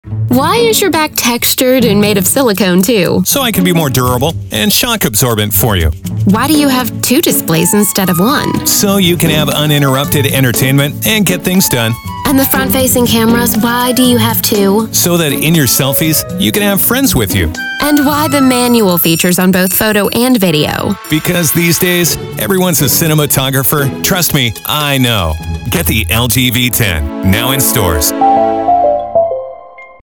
LG-V10-Ad.mp3